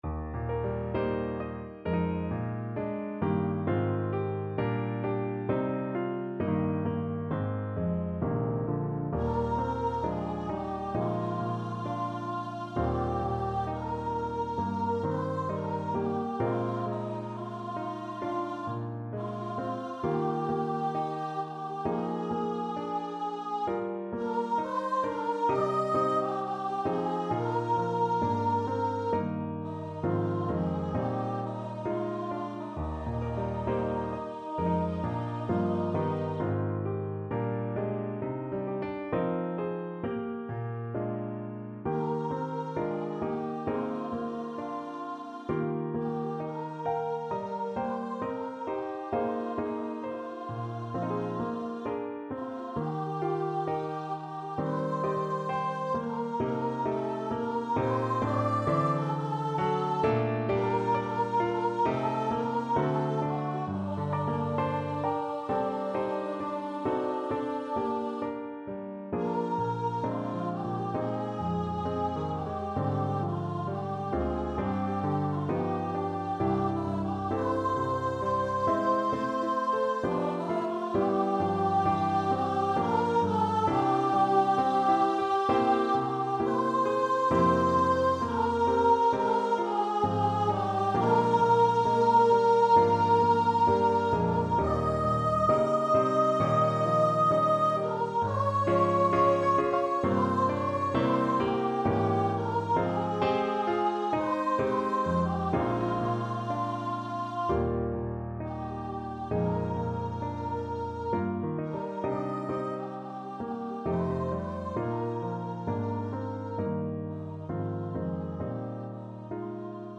4/4 (View more 4/4 Music)
D5-Eb6
Classical (View more Classical Voice Music)